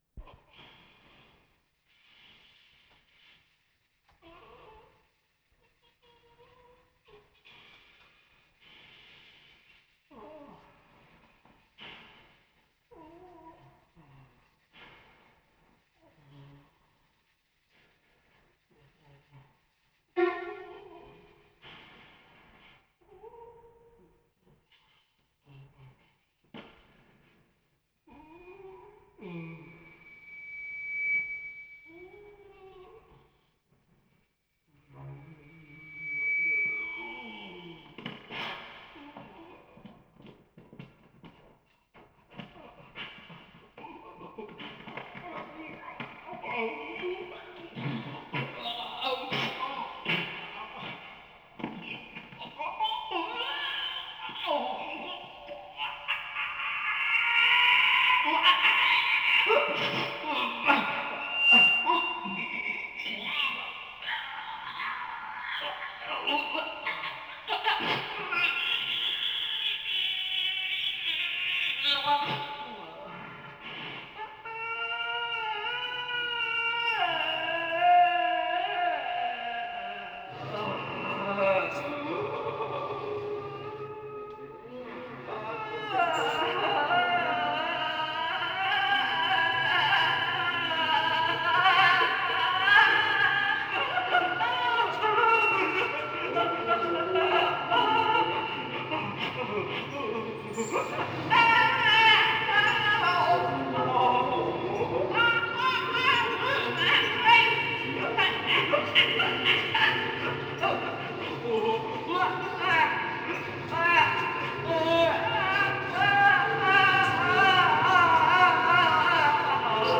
スタジオ録音）